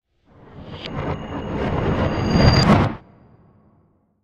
sound effects
georgia-appear.ogg